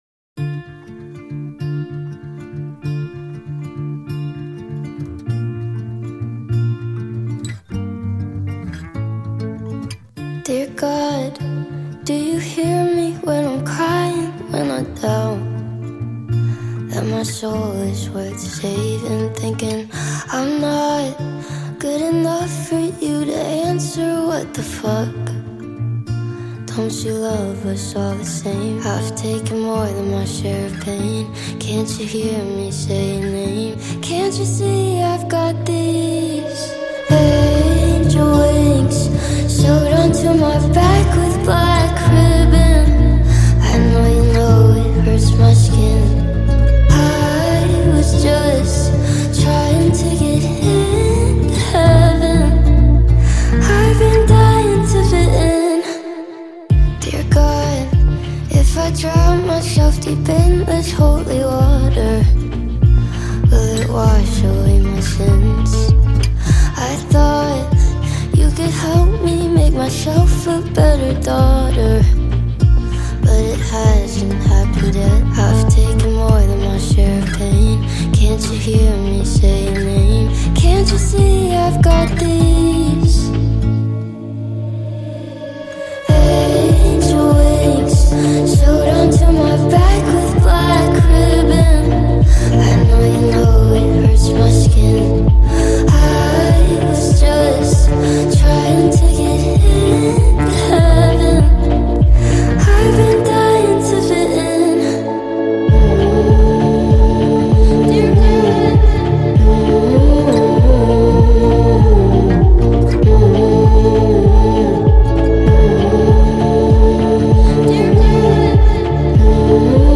Soft Pop